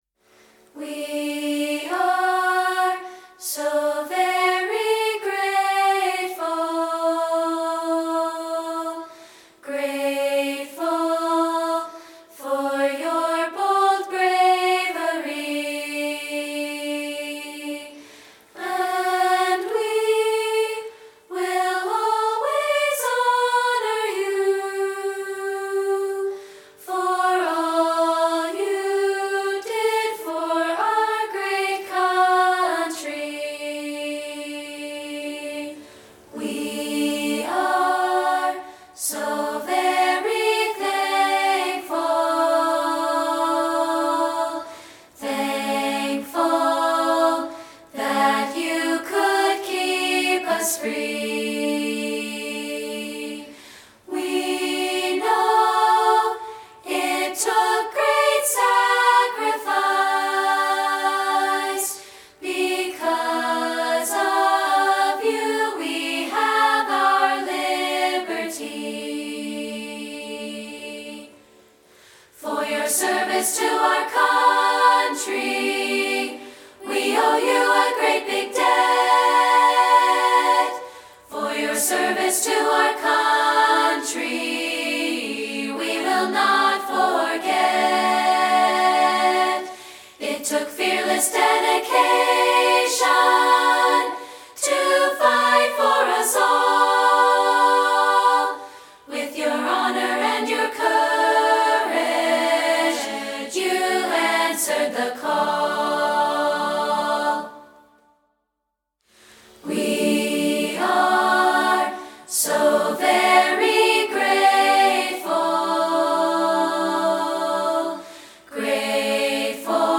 patriotic piece
including this a cappella version.